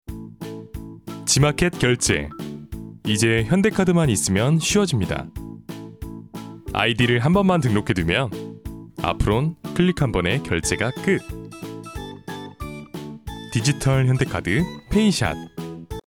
专题广告